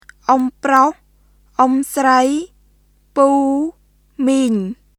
[オム（プロホ／スライ）、プー、ミーン　ʔom (proh / srəi),　puː,　miːŋ]